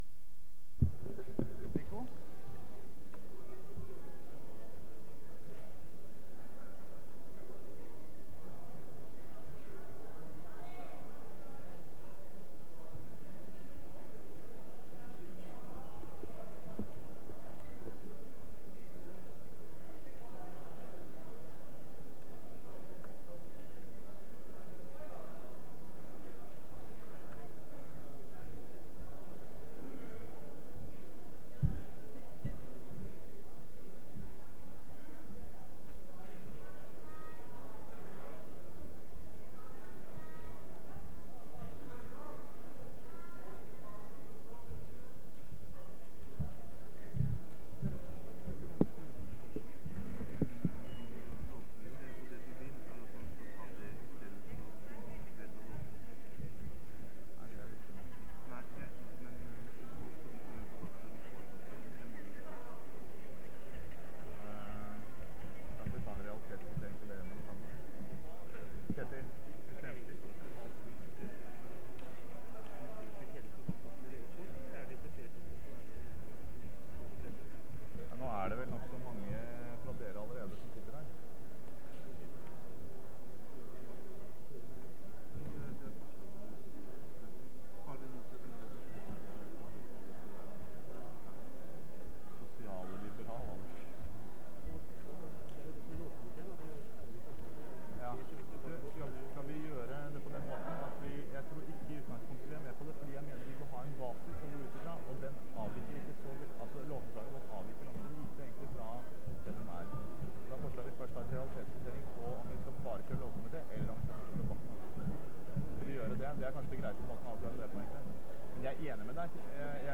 Generalforsamling (kassett)